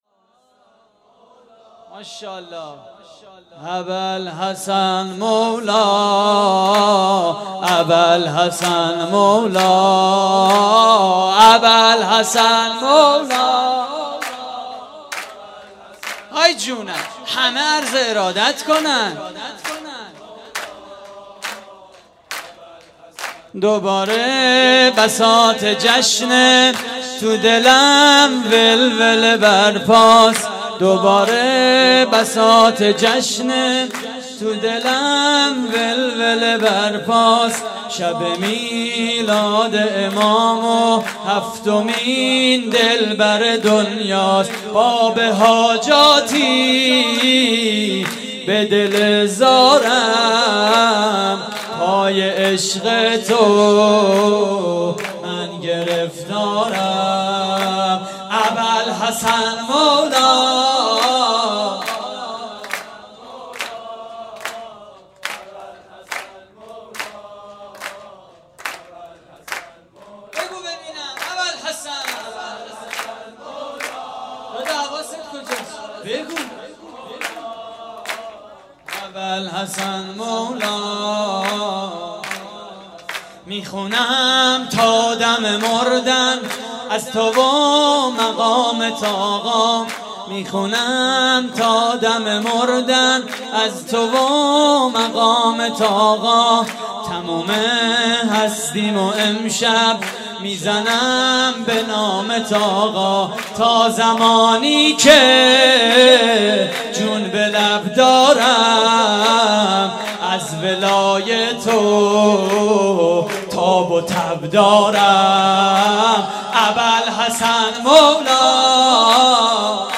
مراسم جشن ولادت امام موسی کاظم (ع) / هیئت زوارالزهرا (س) - نازی آباد؛ 1 مهر 95
صوت مراسم:
سرود: اباالحسن مولا؛ پخش آنلاین |